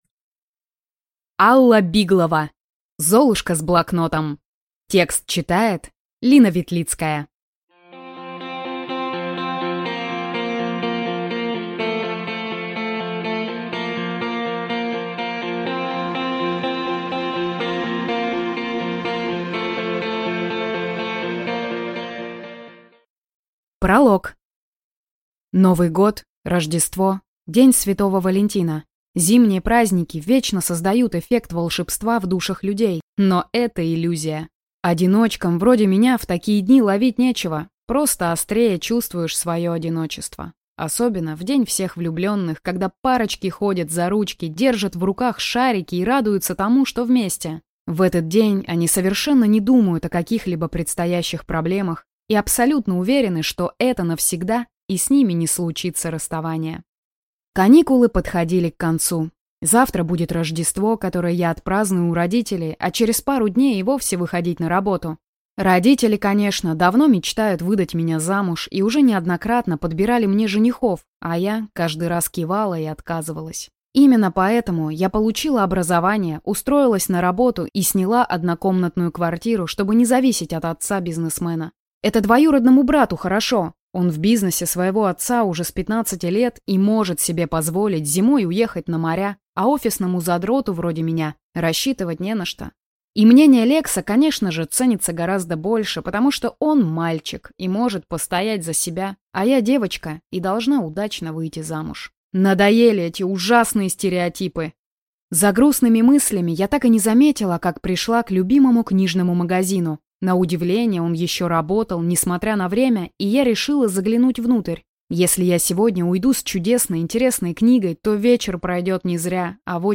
Аудиокнига Золушка с блокнотом | Библиотека аудиокниг
Прослушать и бесплатно скачать фрагмент аудиокниги